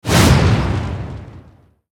soceress_skill_fireball_02_fire.wav